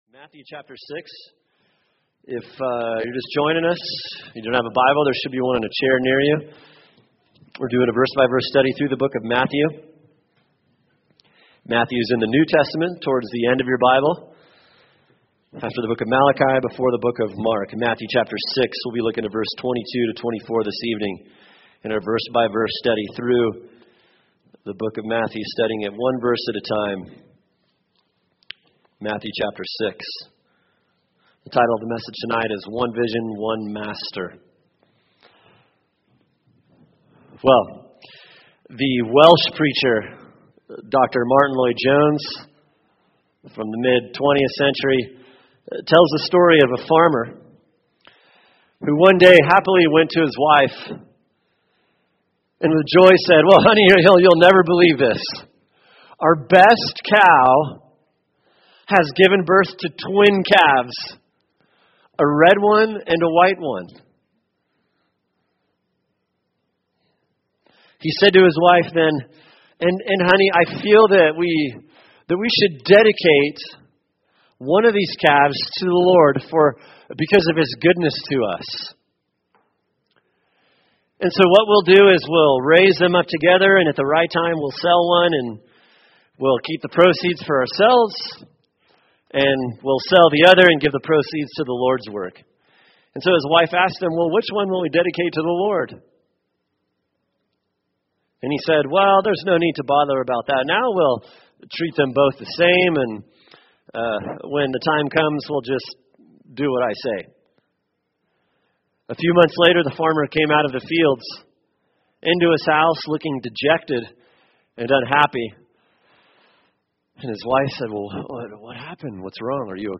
[sermon] Matthew 6:22-24 “One Vision, One Master” | Cornerstone Church - Jackson Hole